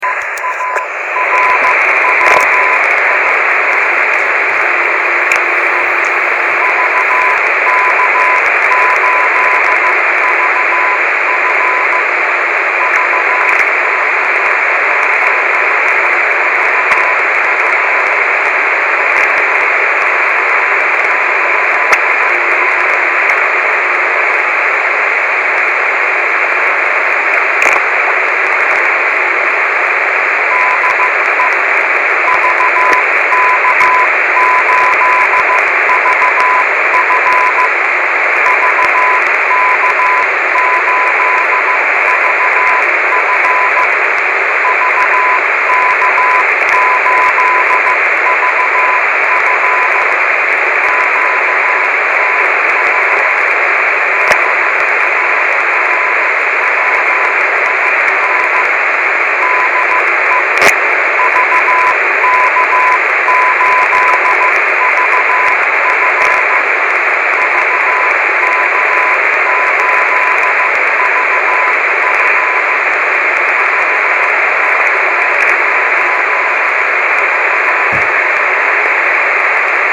Австралийский радиолюбительский маяк. Прнят 09.03.13